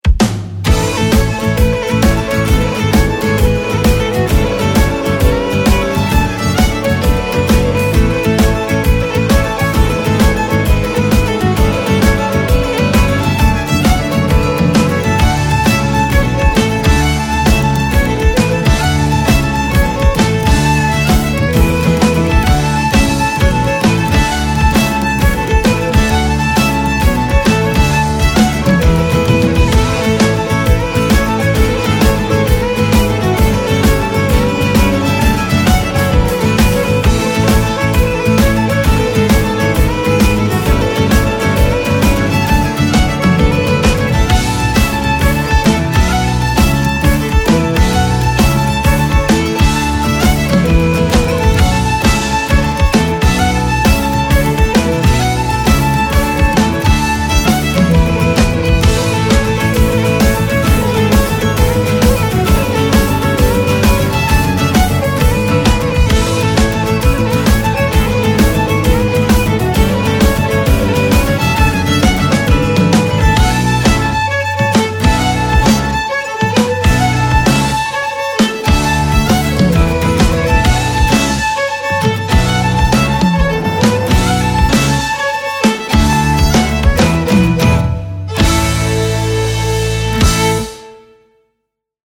The Ceilidh Band are an exiting, young and professional group of musicians specialising in authentic Scottish Ceilidh with a few Irish and English barn dances thrown in for good measure.
• Young, energetic, professional, modern ceilidh band
The Ceilidh band combine traditional ceilidh dances with an uptempo rhythm section that will appeal to guests of all ages.
• 5-piece
Caller, Fiddle, Guitar/Piano, Bass Guitar & Drum Kit